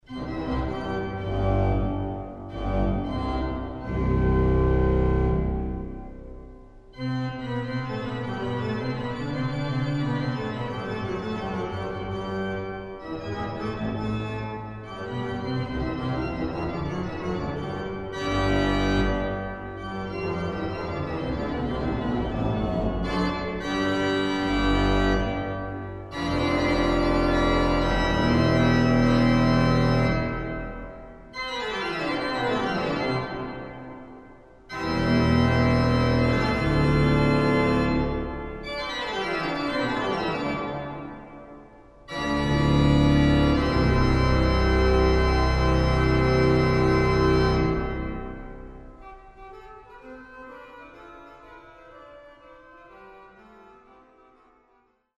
Vårfrukyrkan, Skänninge
vid återinvigningen av orgeln
ORGEL
1938-39 bygger Marcussen & Søn, Aabenraa, Danmark, bygger en tremanualig orgel med 34 stämmor,